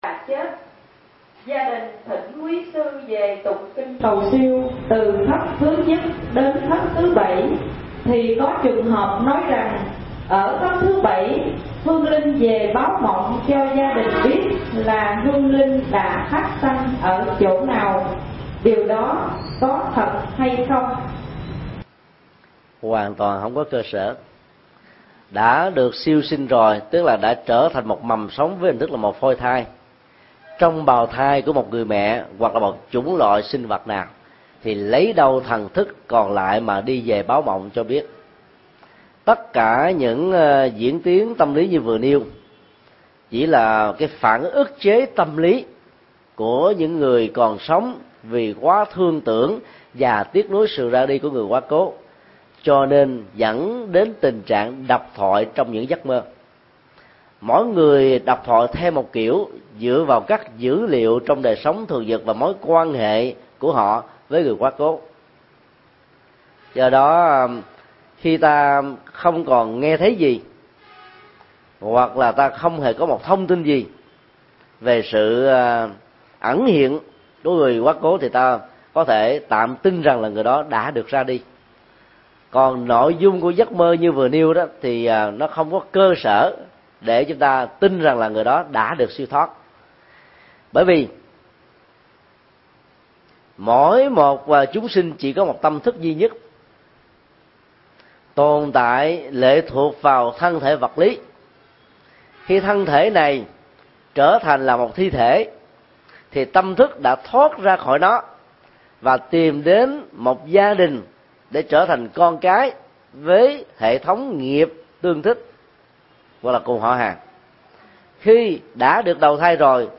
Vấn đáp: Quan niệm về “hương linh báo mộng”